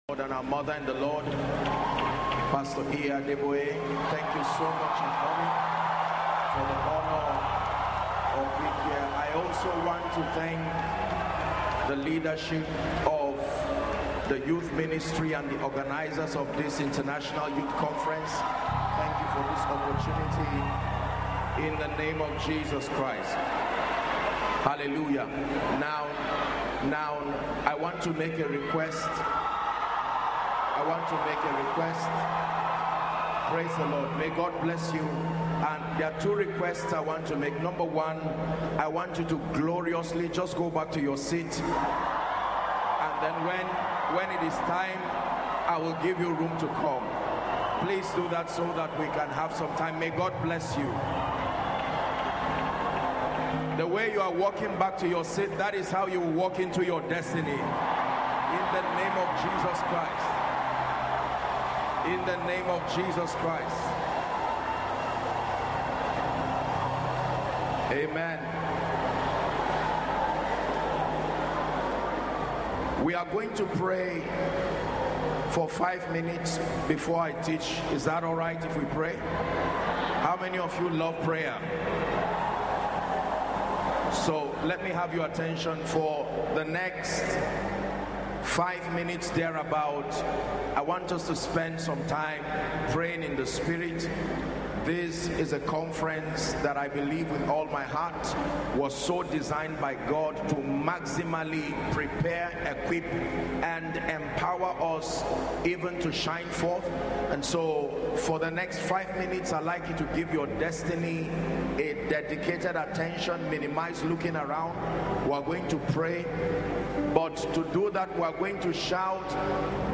The Redeemed Christian Church of God presents her annual International Youth Convention IYC Tagged, ” Shine Forth”